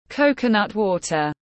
Nước dừa tiếng anh gọi là coconut water, phiên âm tiếng anh đọc là /ˈkəʊ.kə.nʌt ˌwɔː.tər/